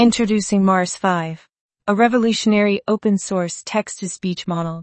text-to-speech voice-cloning
A novel speech model for insane prosody.